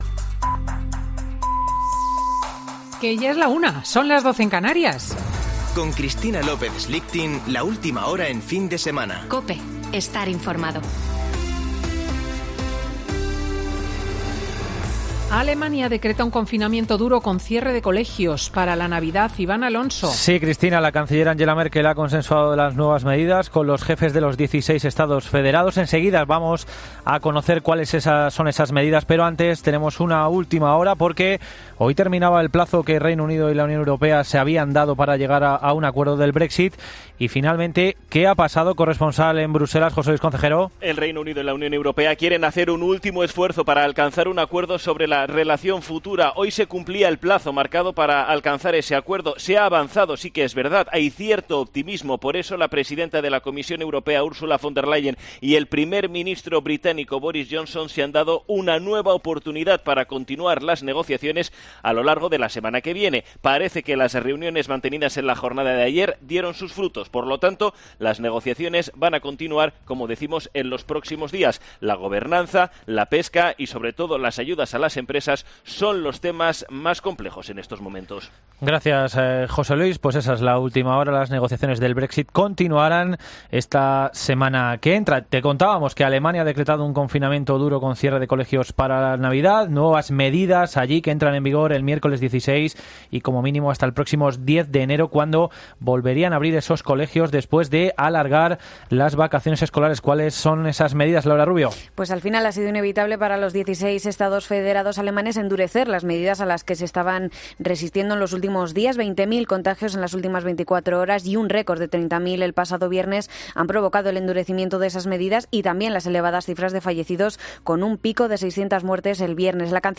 Boletín de noticias COPE del 13 de diciembre de 2020 a las 13.00 horas